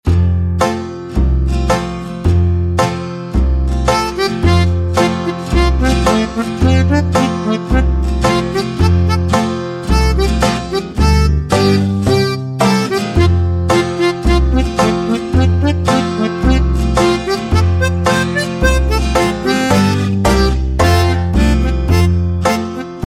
mp3 Instrumental Song Track